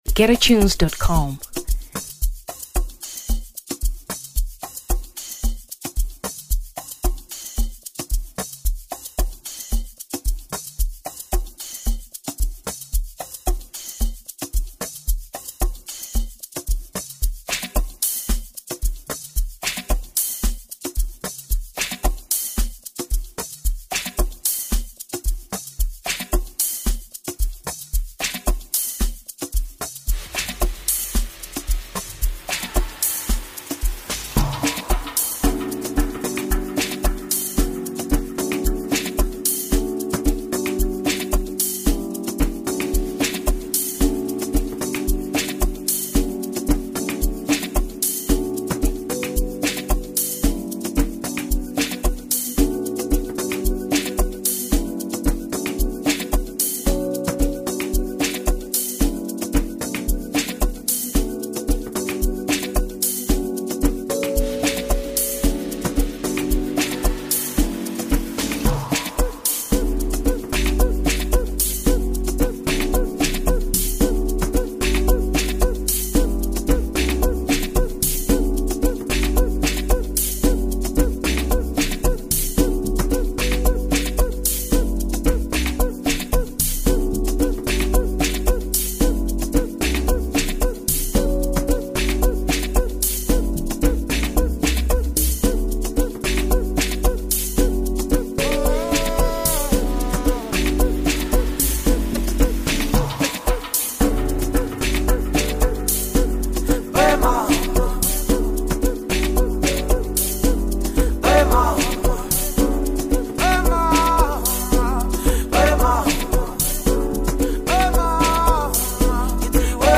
Amapiano 2023 South Africa